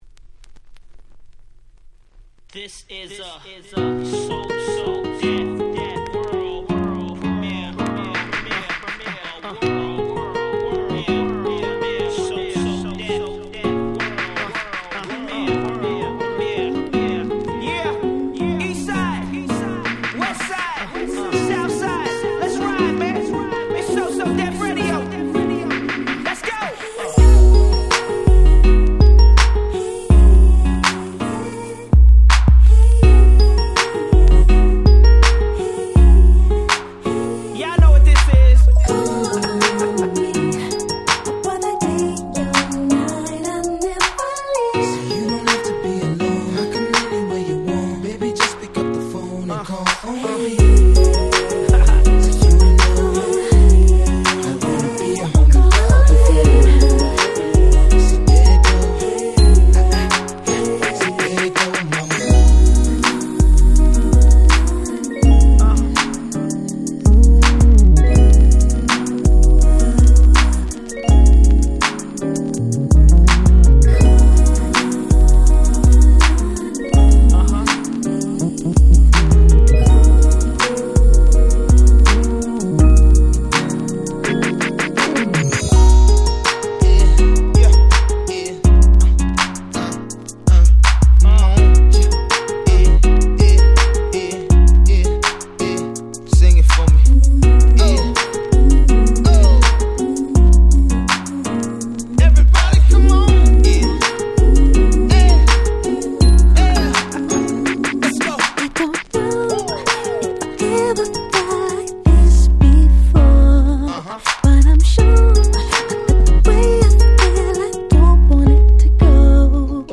06' Super Hit R&B !!
スロウジャム Slow Jam